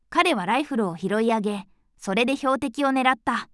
voicevox-voice-corpus / ita-corpus /No.7_ノーマル /EMOTION100_036.wav